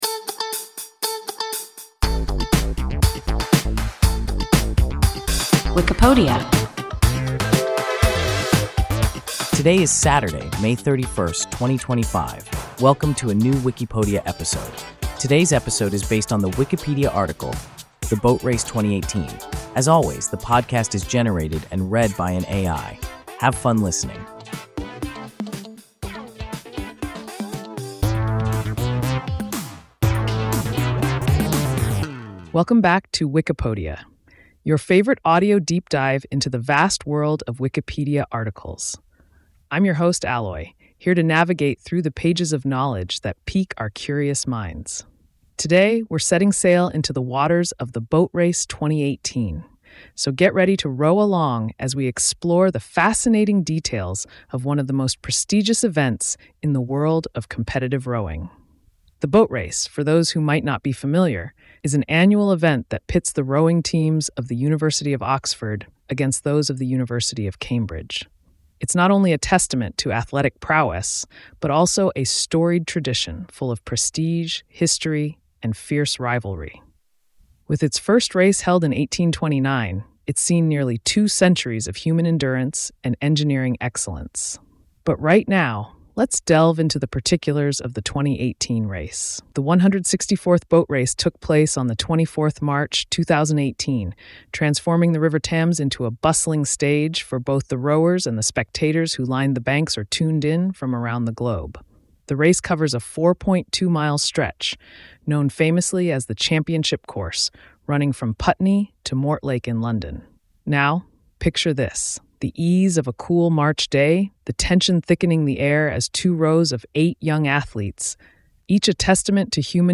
The Boat Race 2018 – WIKIPODIA – ein KI Podcast